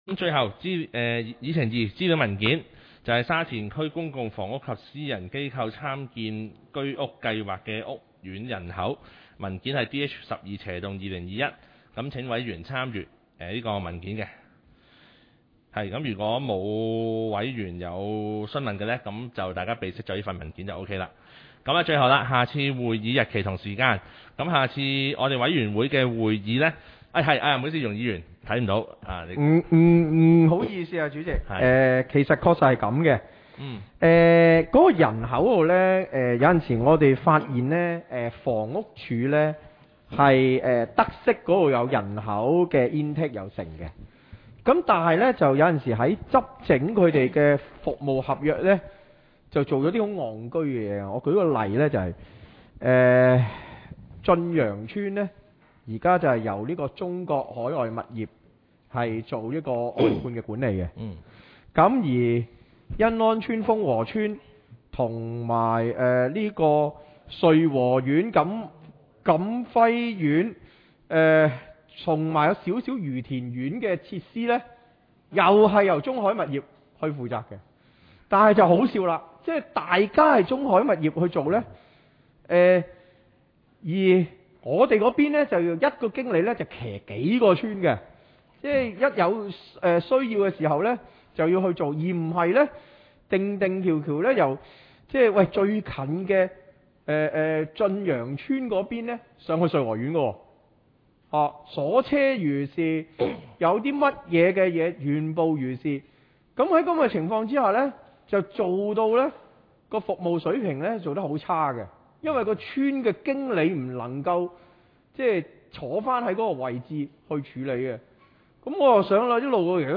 委员会会议的录音记录
地点: 沙田民政事务处411会议室